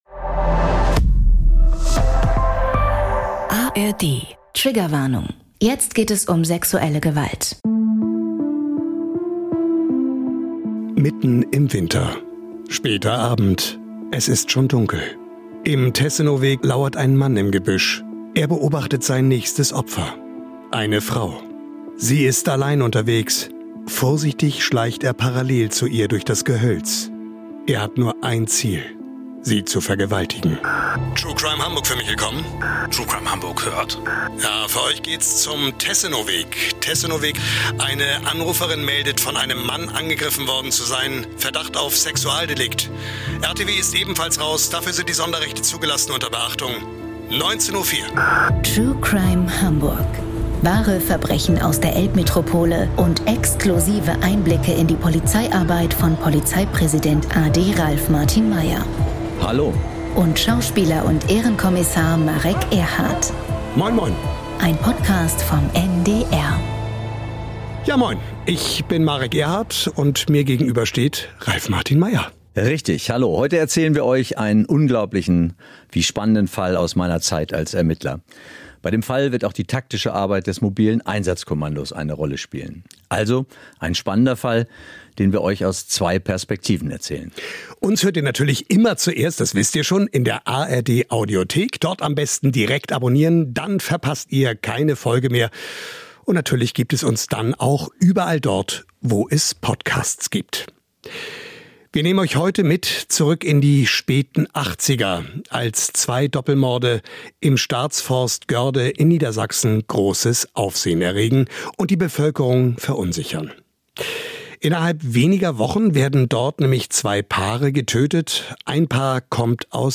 Ein Lockvogel soll den Vergewaltiger auf frischer Tat überführen. Im Gespräch mit Schauspieler Marek Erhardt erklärt Hamburgs Polizeipräsident a.D. Ralf Martin Meyer, wie Streifengänge, Observationen und Präventionsarbeit eingesetzt werden und welche Risiken damit verbunden sind – sowohl für die Ermittler als auch für die Öffentlichkeit.